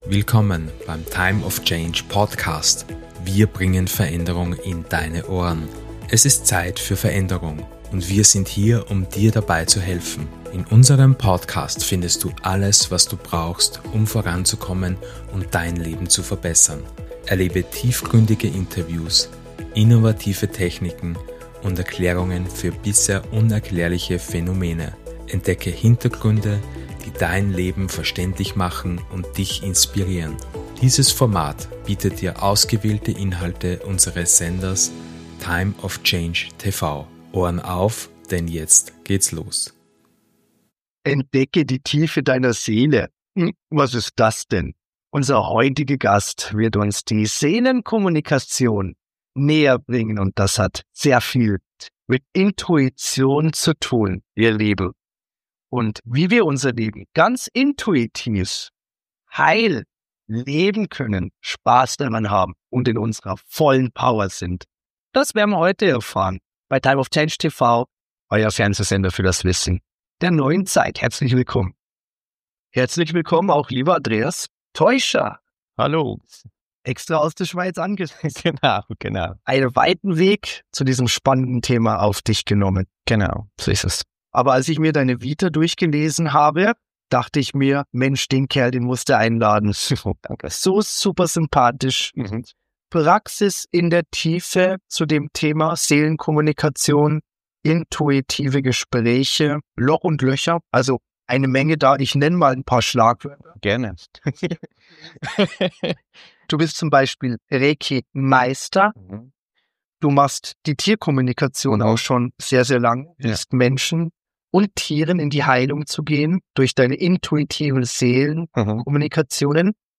Dieses Interview ist für Dich, wenn Du bereit bist, Muster zu durchbrechen & neu zu leben.